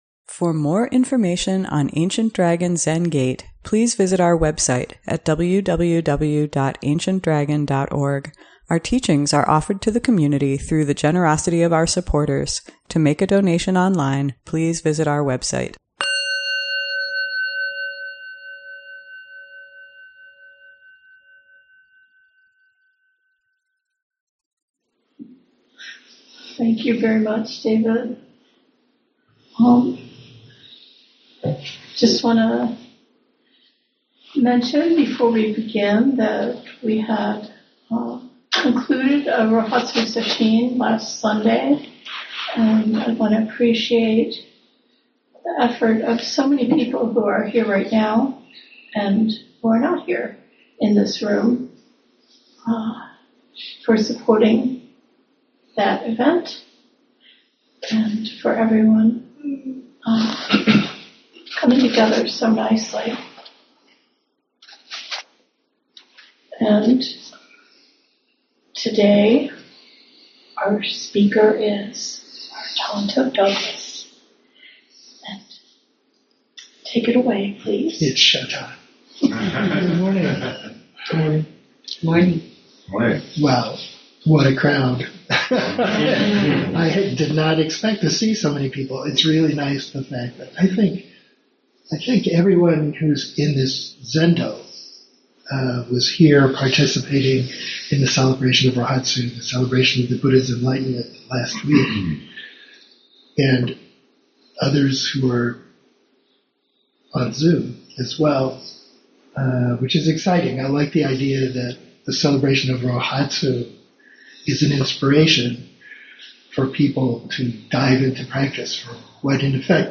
Sunday Morning Dharma Talk